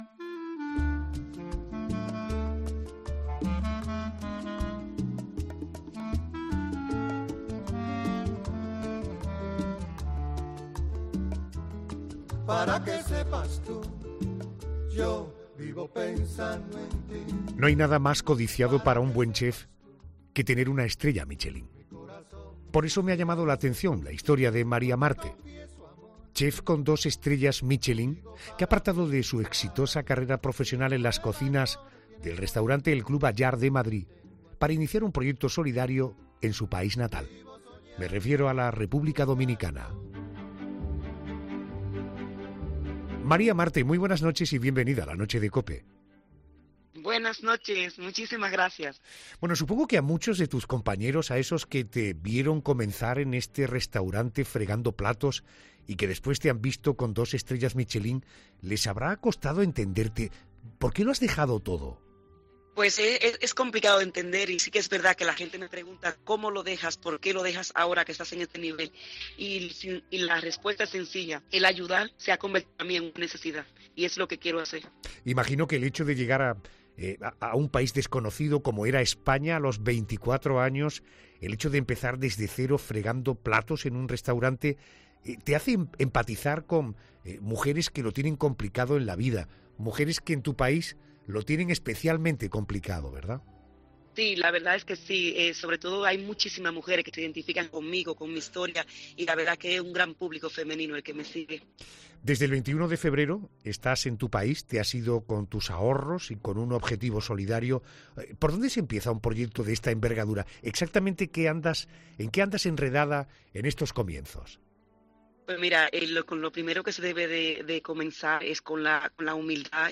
La chef con dos estrellas Michelín, María Marte, desvela en ‘La Noche de COPE’ su nuevo proyecto vital. Un proyecto solidario que la ha hecho renunciar a su trabajo en la cocina del restaurante El Club Allard de Madrid y comenzar una nueva vida en su República Dominicana natal.